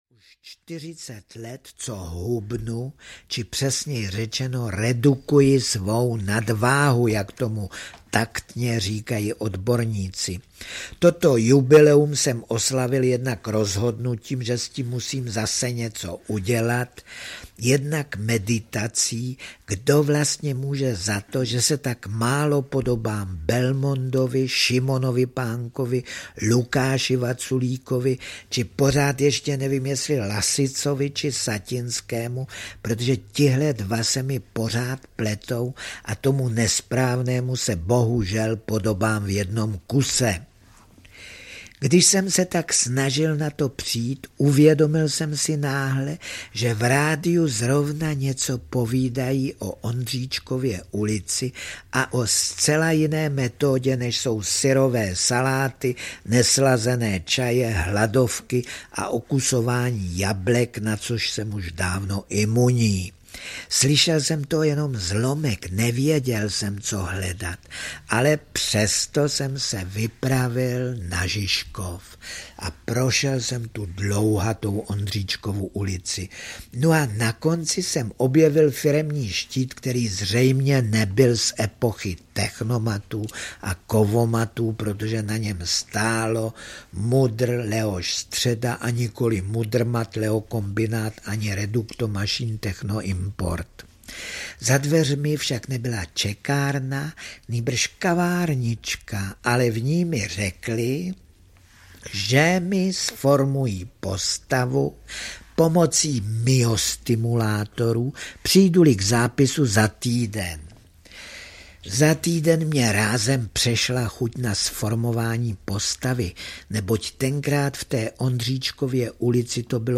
Dobré a ještě lepší pondělí audiokniha